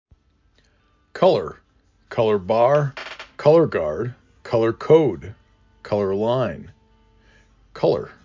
k uh l er
k aw l er
Local Voices
Illinois